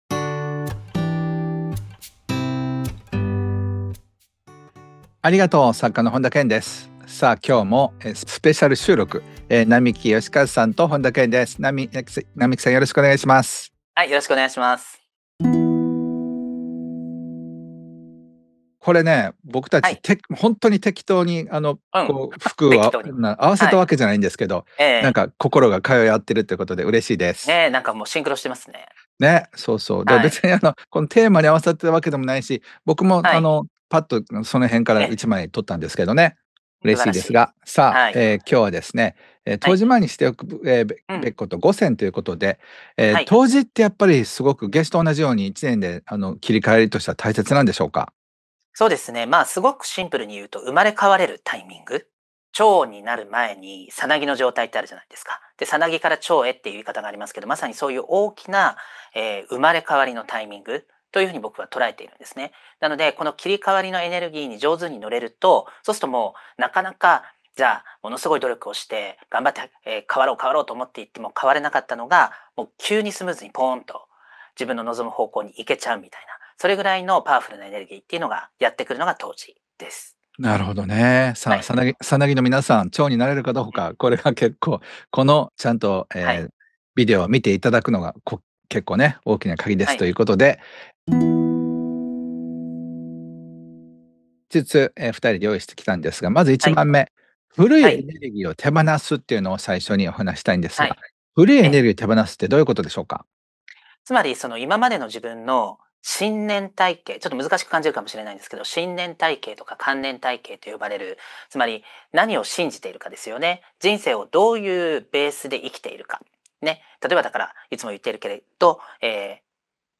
対談